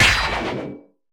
knockeddown_1.ogg